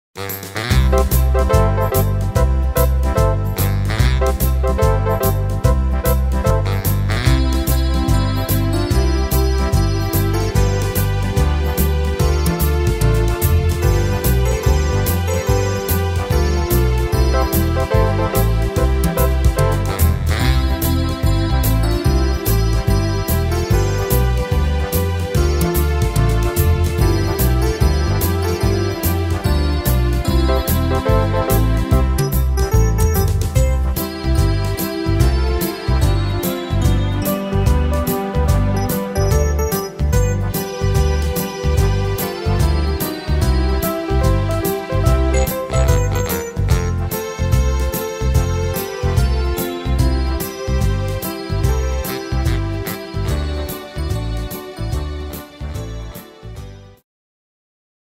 Tempo: 146 / Tonart: C-Dur